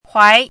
chinese-voice - 汉字语音库
huai2.mp3